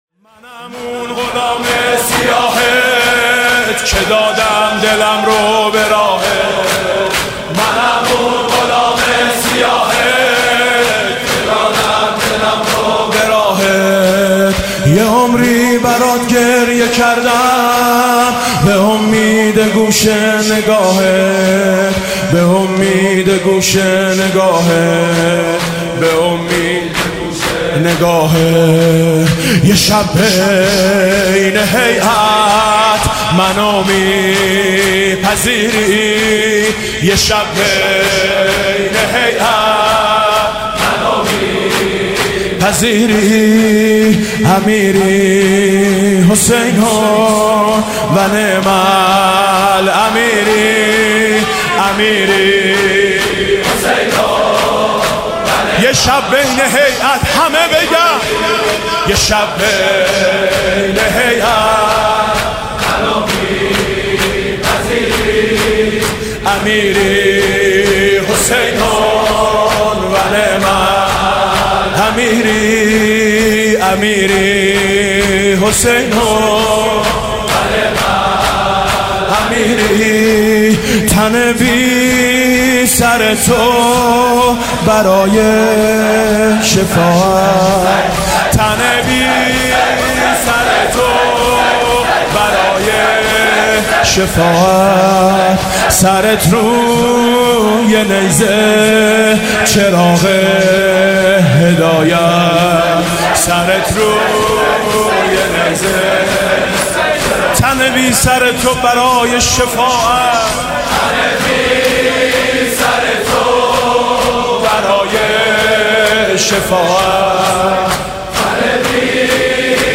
مداحی زیبای حاج میثم مطیعی در شب چهارم محرم انتشار یافت.